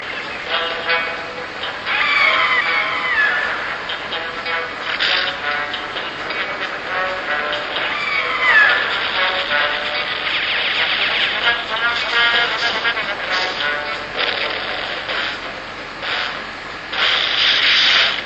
First Theme Music